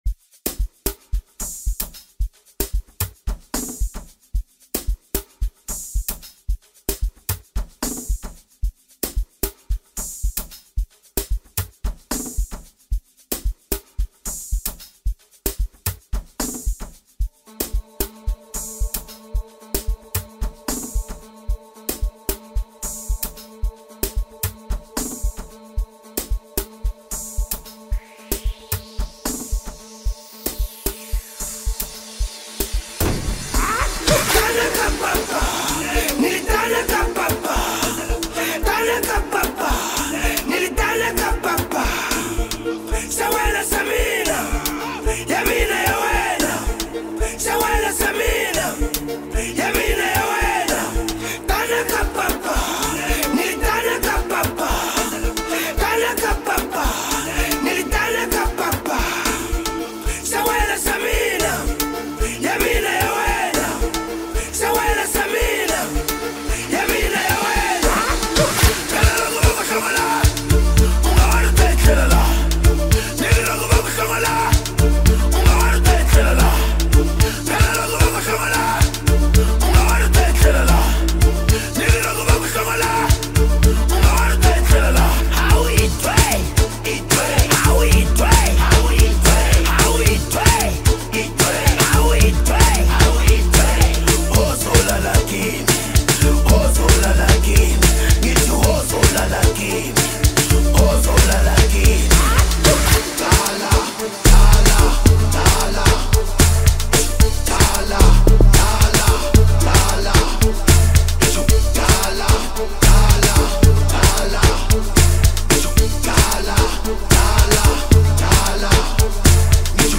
A well-known African music trio